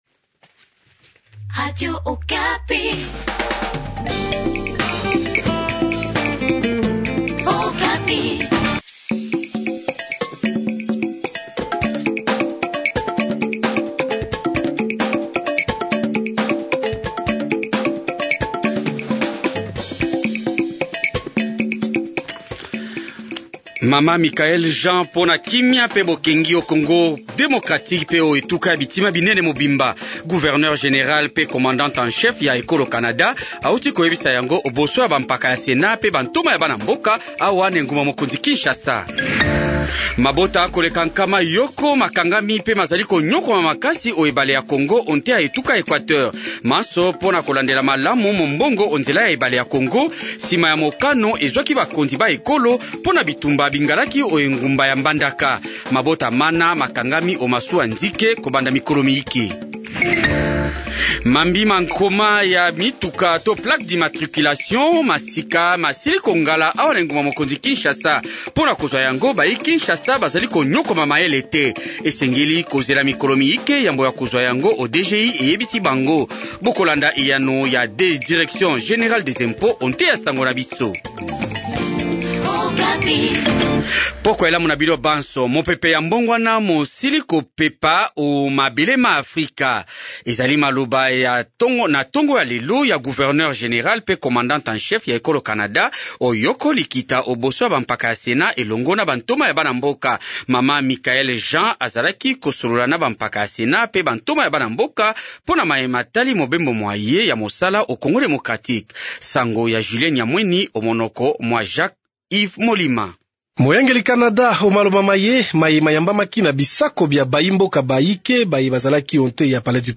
Journal lingala du soir